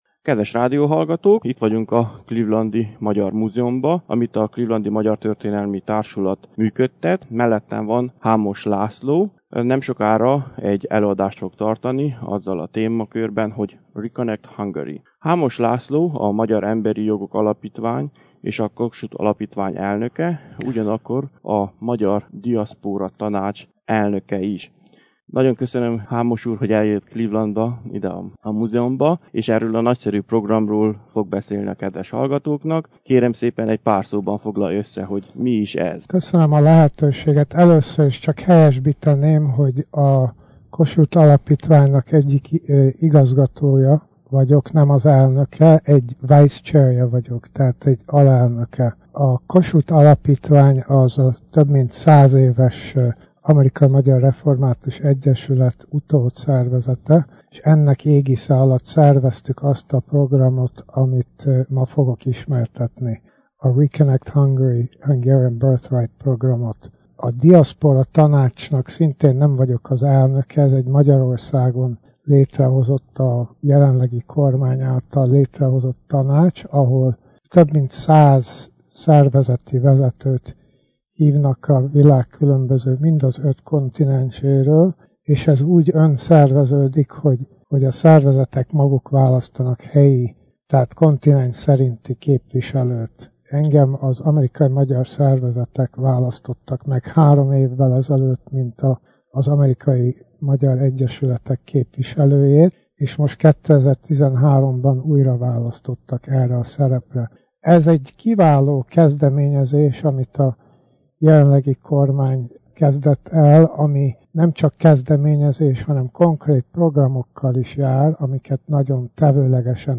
„Re-connect Hungary” [interjú]